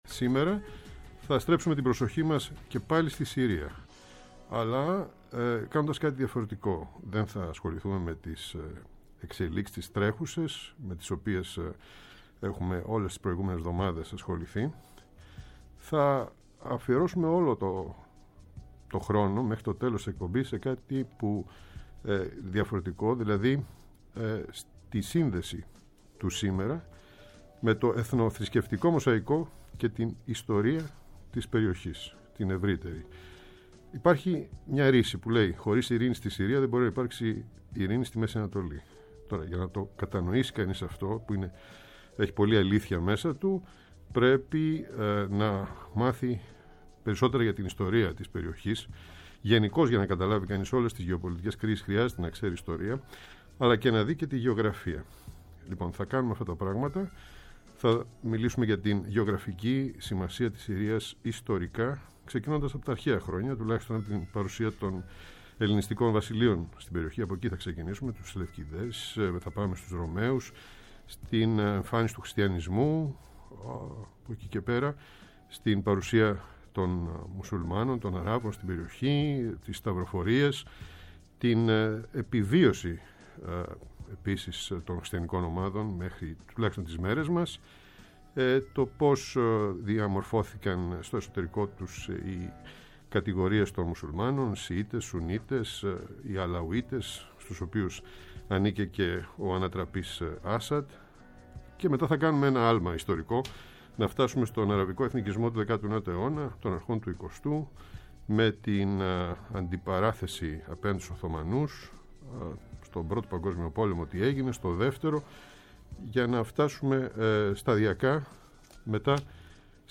Προσπαθώντας να κατανοήσουμε το νόημα της, η συζήτηση ξεκινά με το ποια είναι η γεωγραφική/γεωπολιτική και κοινωνική σημασία της Συρίας διαμέσου των αιώνων, αρχίζοντας από την εποχή των μετα-Αλεξανδρινών, ελληνιστικών βασιλείων των Σελευκιδών, της κατάληψης της περιοχής από τους Ρωμαίους και την εμφάνιση των πρώτων χριστιανών που επιβιώνουν έως σήμερα, αν και με αμφιβολίες για το μέλλον τους.